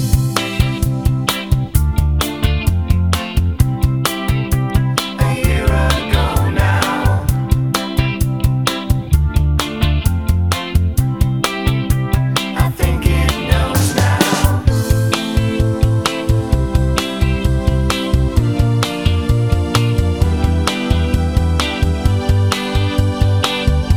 Professional Pop (1970s) Backing Tracks.